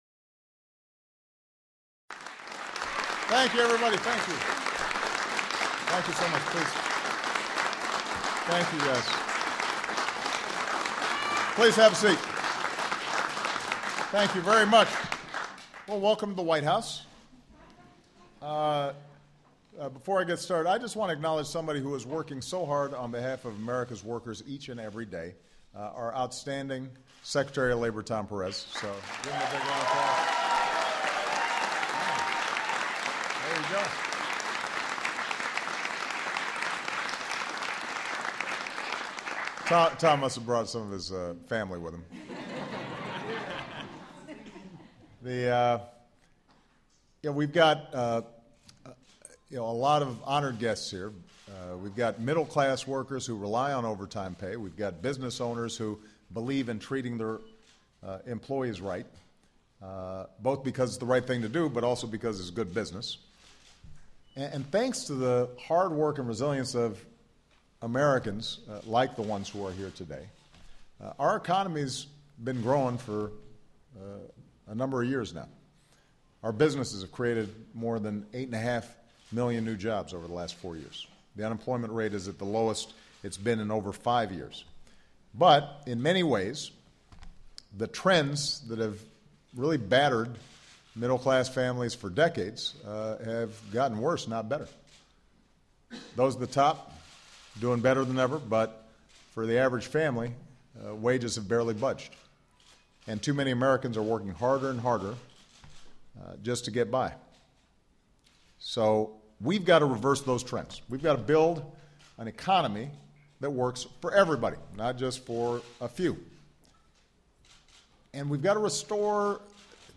Speakers Obama, Barack